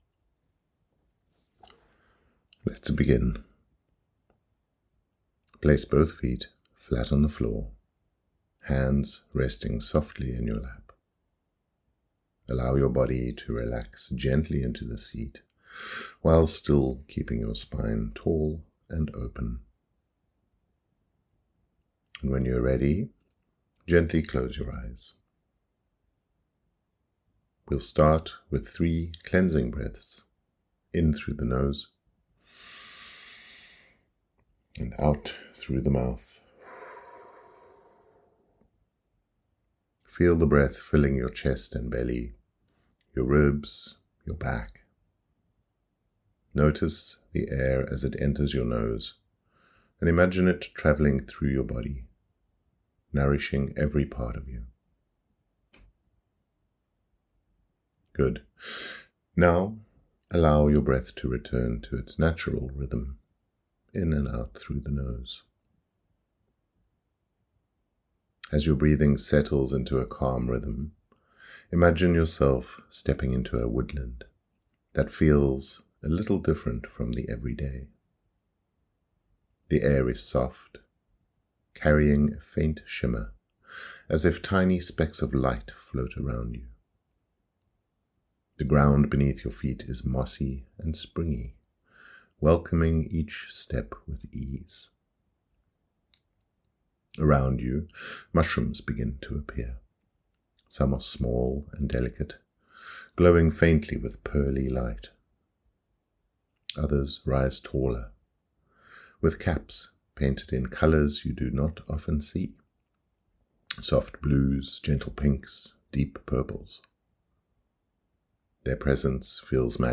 Mushroom Magic Meditation
YE08-meditation.mp3